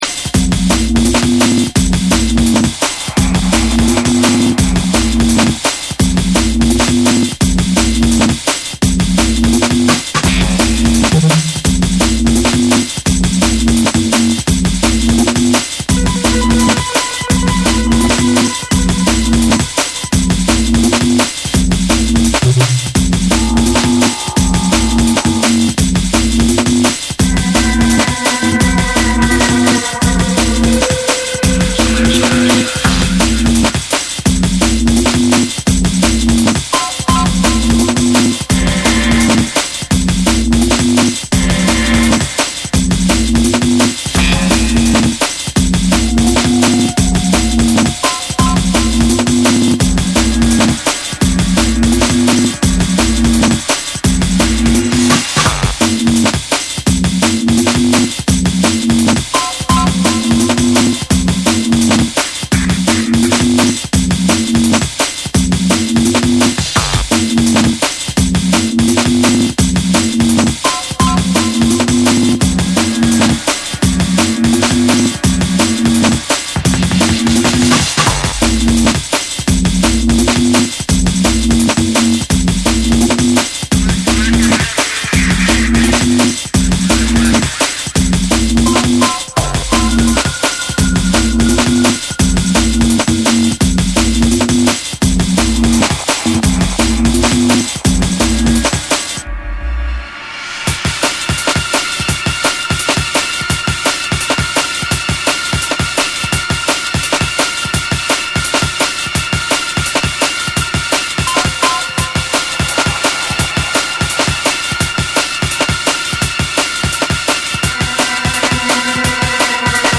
90sドラムンベースを象徴する伝説の女性デュオ
タイトなビート、ダークでハードなベースライン、そして張り詰めた緊張感。
JUNGLE & DRUM'N BASS